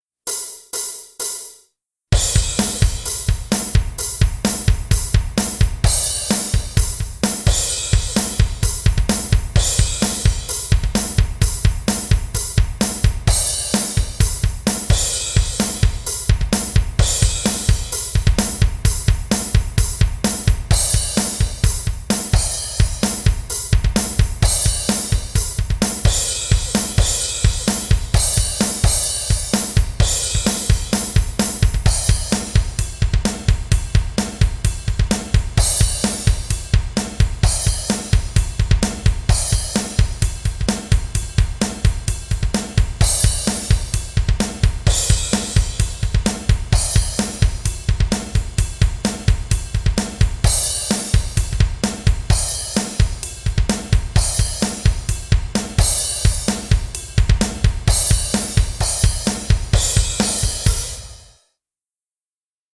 music_brickandmortar_startupoptical_drums.mp3